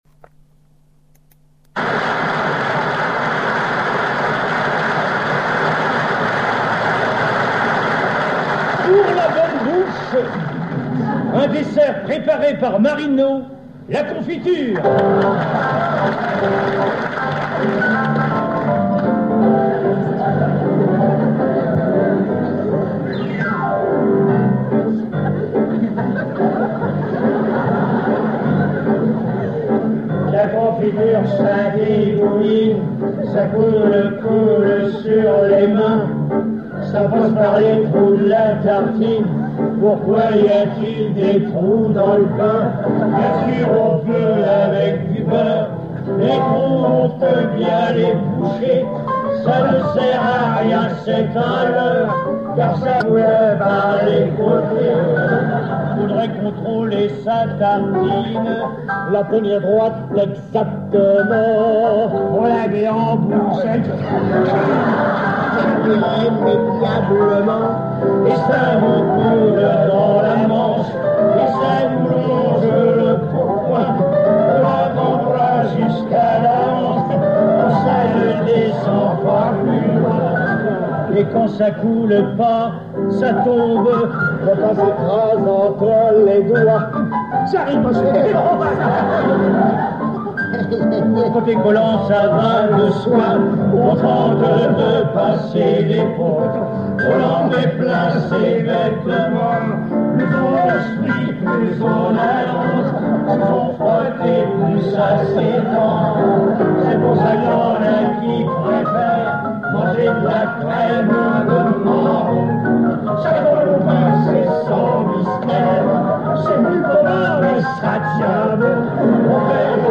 chanson drôlement réaliste
en public